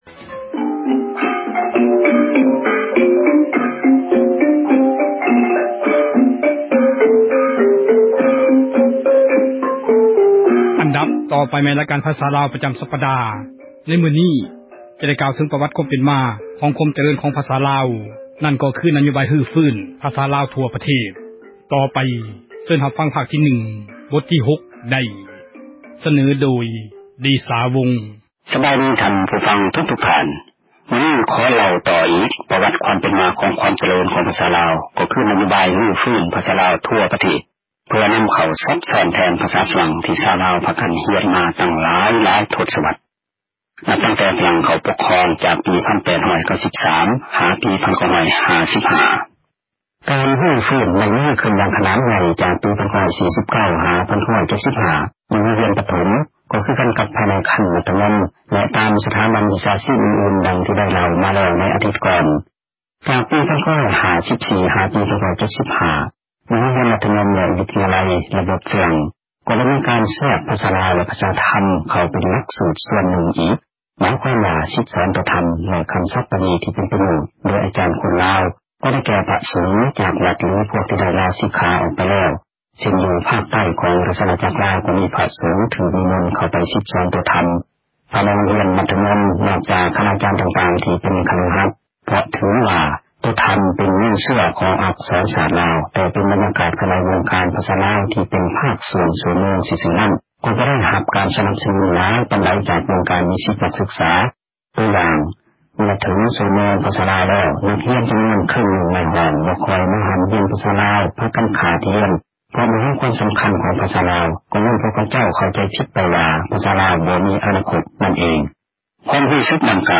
ບົດຮຽນ ພາສາລາວ